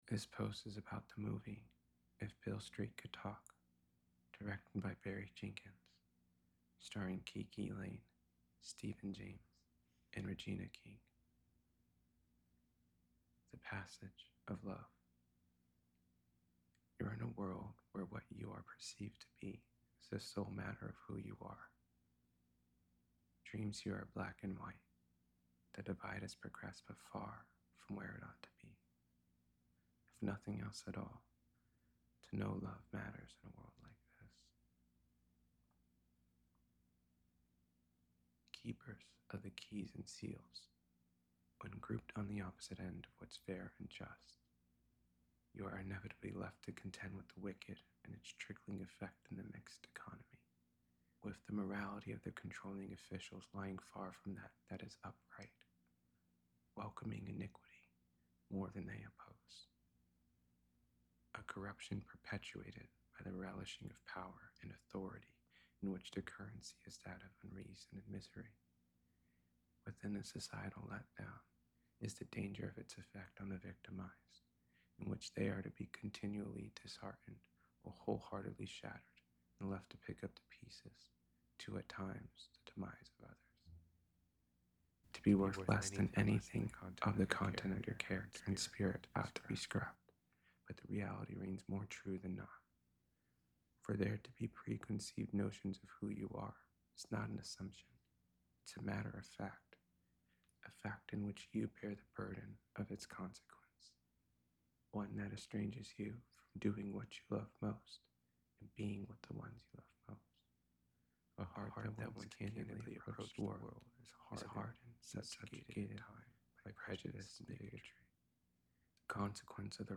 if-beale-street-could-talk-to-know-a-story-reading.mp3